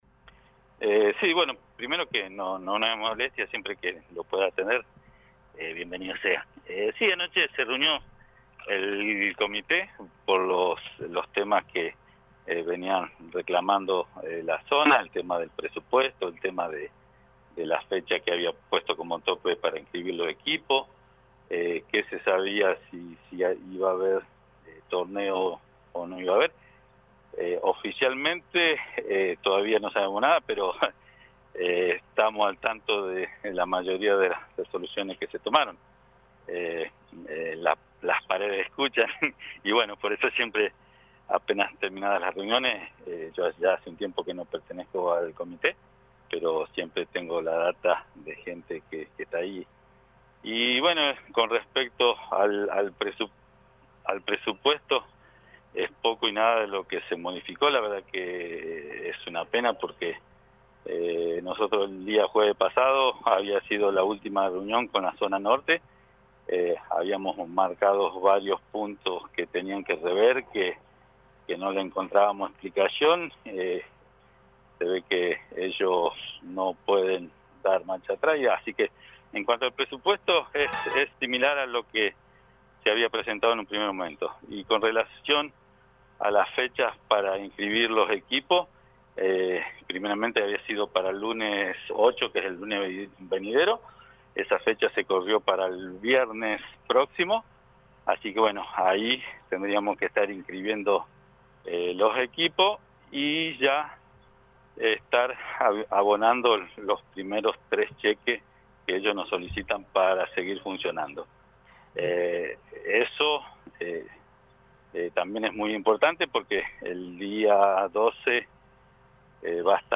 en dialogo con LA RADIO 102.9.